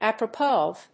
アクセントapropós of…